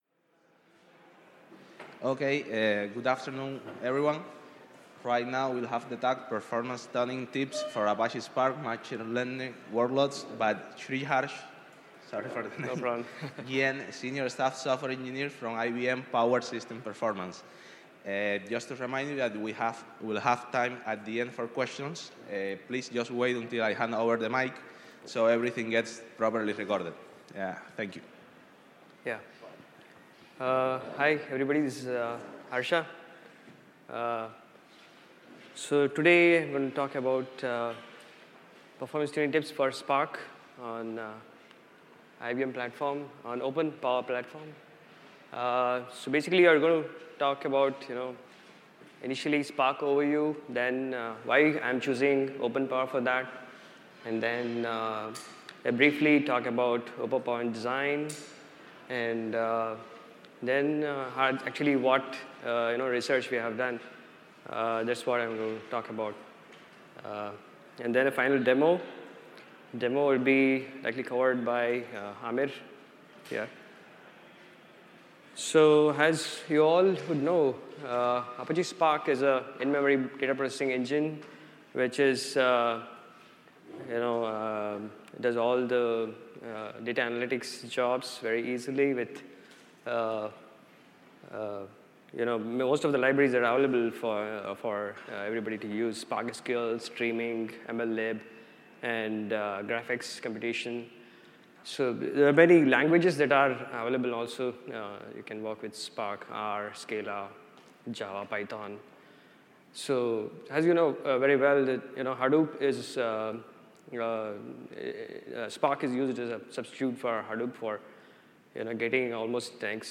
Apache Big Data Seville 2016 – Performance Tuning Tips for Apache Spark Machine Learning Workloads